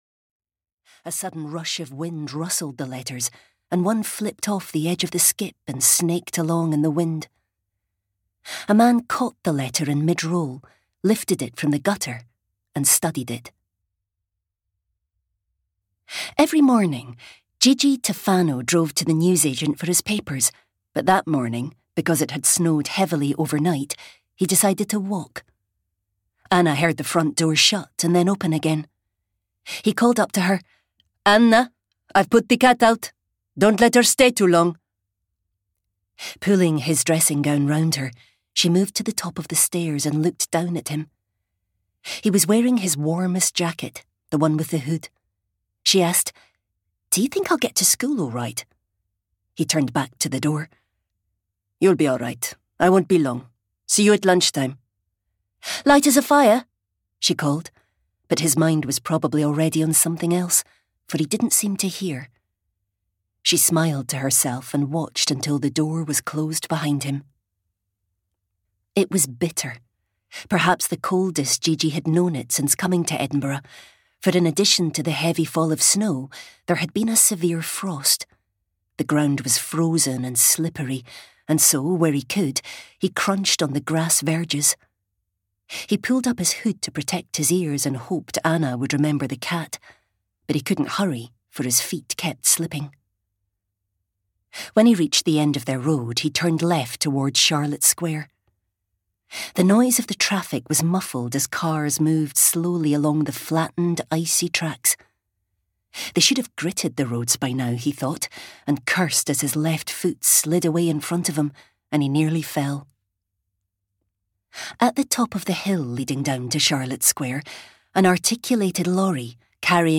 Lights on Dark Water (EN) audiokniha
Ukázka z knihy